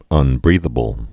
(ŭn-brēthə-bəl)